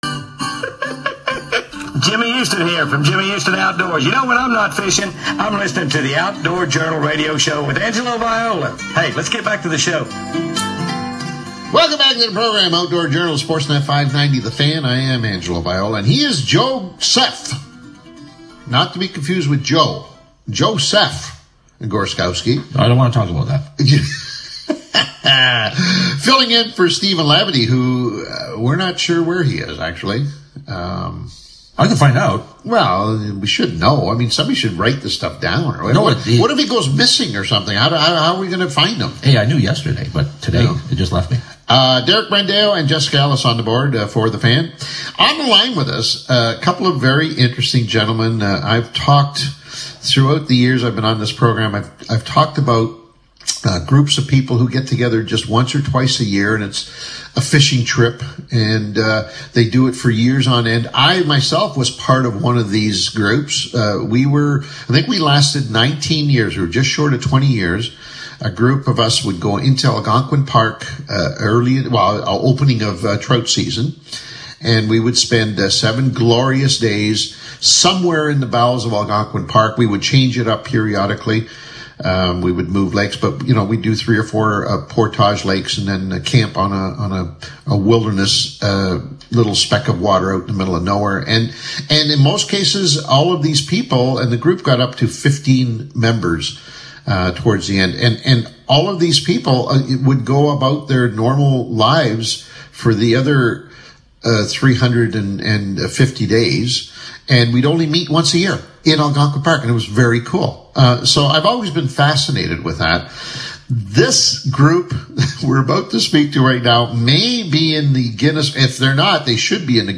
THE INTERVIEW
on the radio